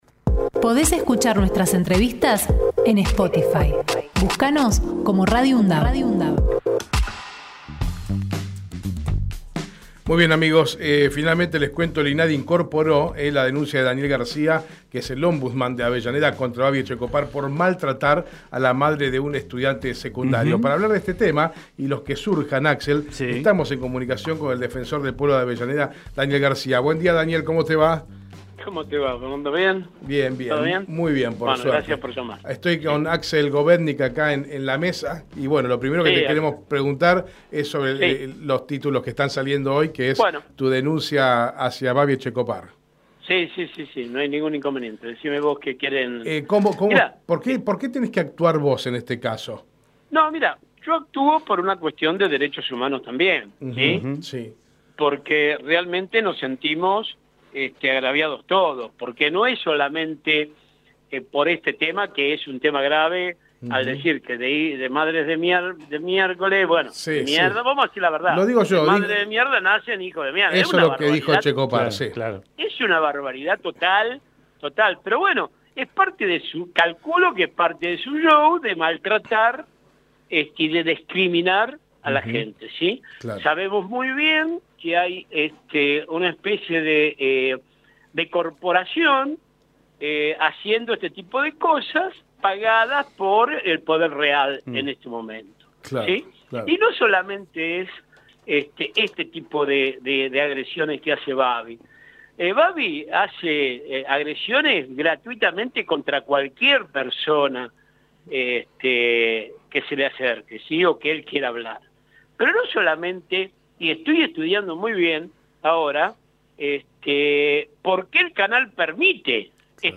Daniel Garcia en Hacemos PyE Texto de la nota: Compartimos la entrevista realizada en Hacemos PyE a Daniel García, defensor del Pueblo de Avellaneda . Conversamos sobre la denuncia realizada a Baby Etchecopar por maltrato a una madre de un estudiante secundario.